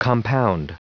Prononciation du mot compound en anglais (fichier audio)
Prononciation du mot : compound